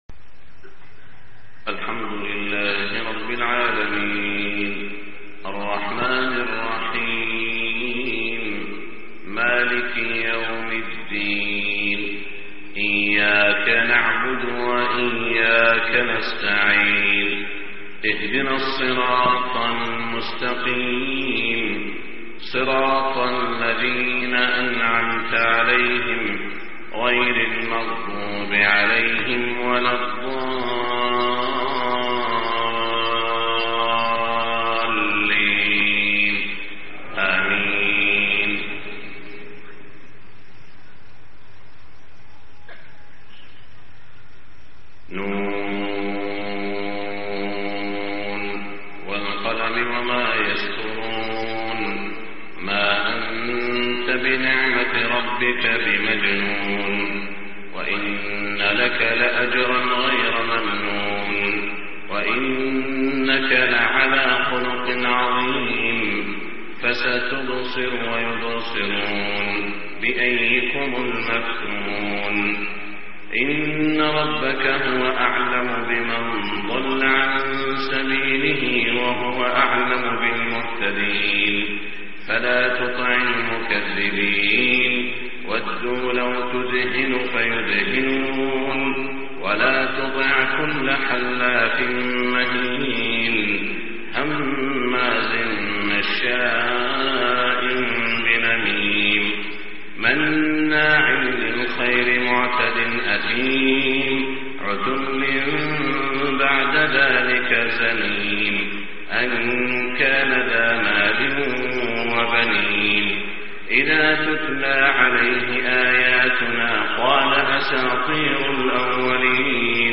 صلاة الفجر 5-8-1426 سورة القلم > 1426 🕋 > الفروض - تلاوات الحرمين